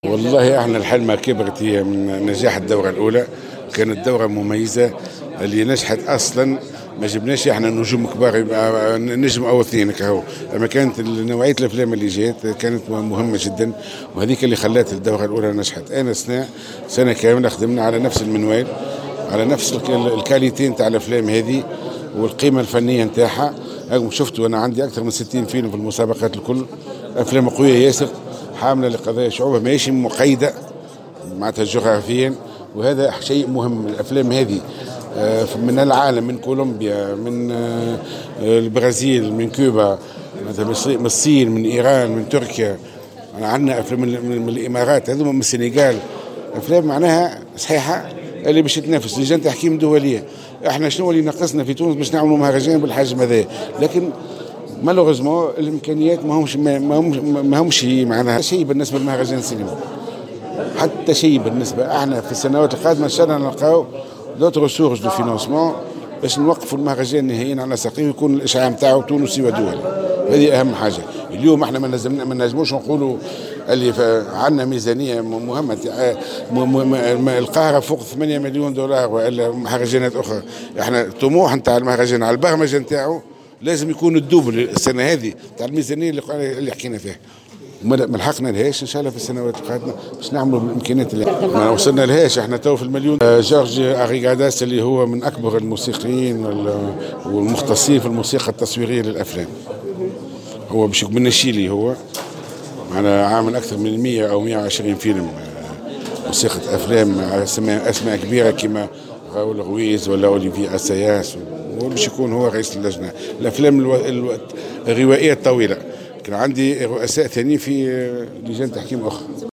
إنطلاق الدورة الثانية للمهرجان السينمائي الدولي ياسمين الحمامات (تصريح+صور)